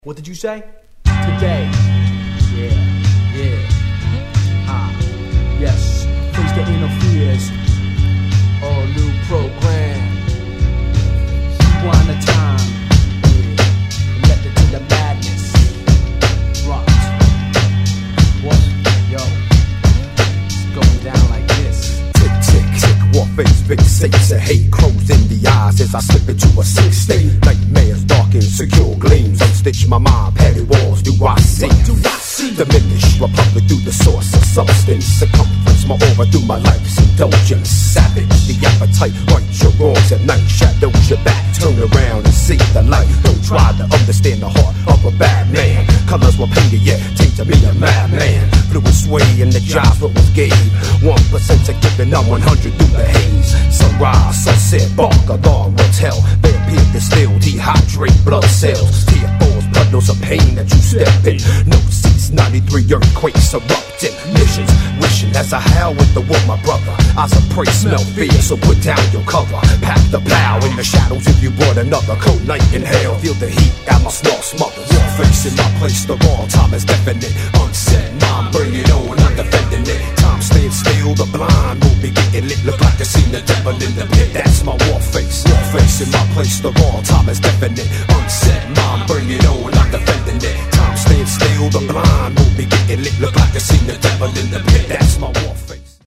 両面共に◎なNY Mid 90's Underground Hip Hop!!